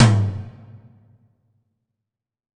WTOM 1.wav